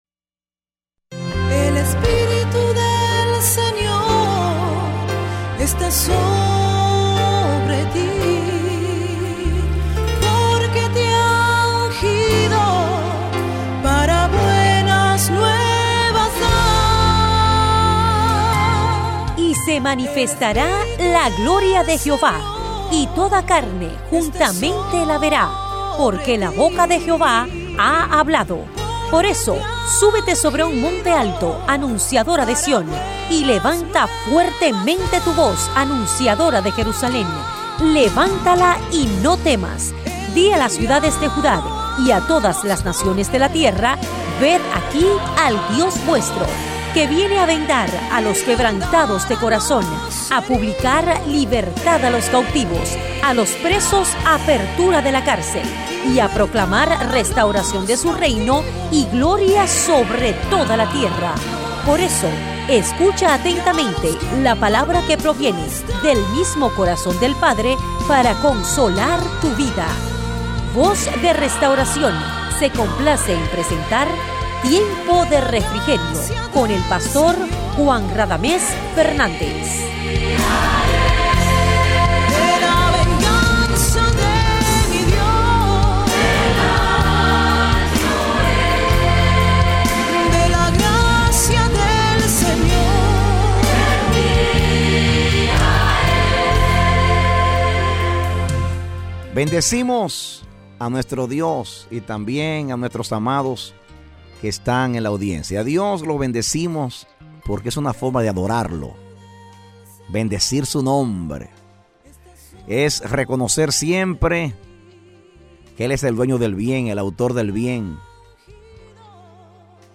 Mensaje: “Entendiendo a Dios”
De la Serie: "Programas Radiales"